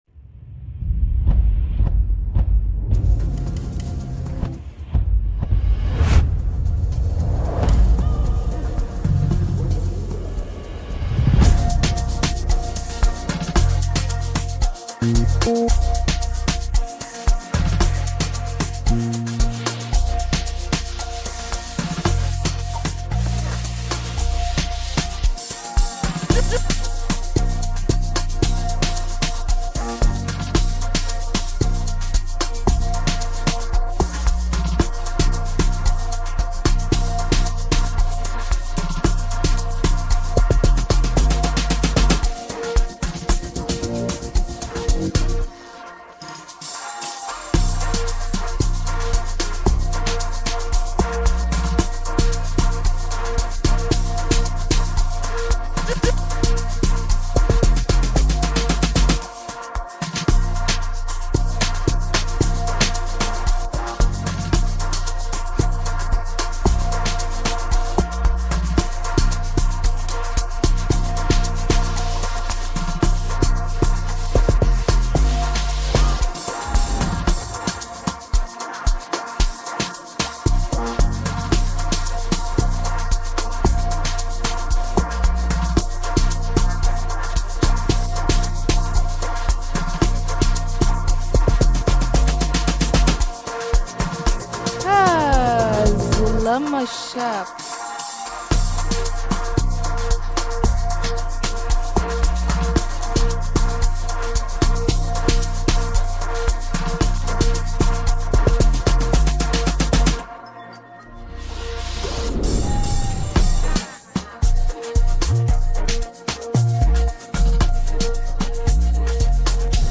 afrobeats , amapiano